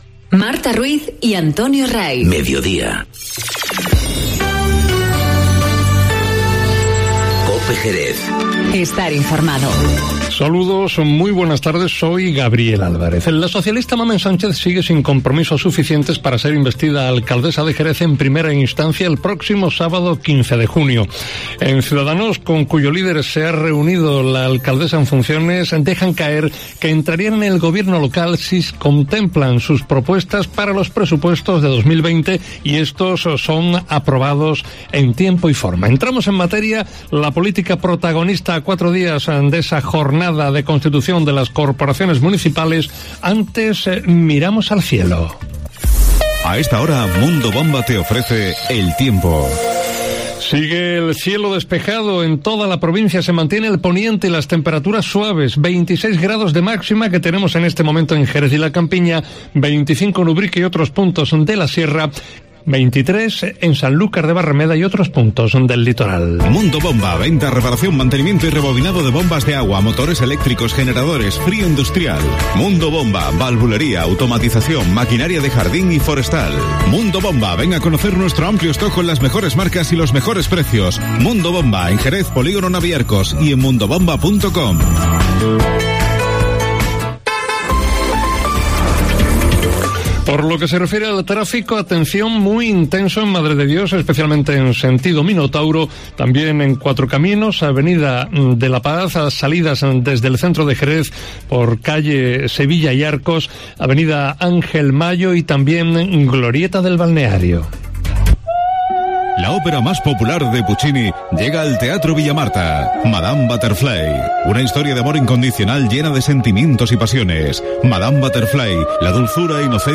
Informativo Mediodía COPE en Jerez 11-06-19